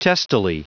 Prononciation du mot testily en anglais (fichier audio)
Prononciation du mot : testily